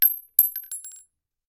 8abddf23c7 Divergent / mods / Bullet Shell Sounds / gamedata / sounds / bullet_shells / rifle_generic_6.ogg 26 KiB (Stored with Git LFS) Raw History
rifle_generic_6.ogg